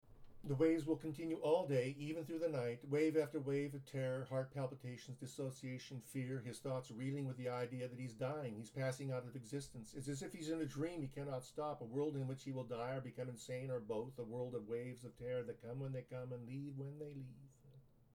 I plan to continue writing poems that need to be recited aloud using only one breath.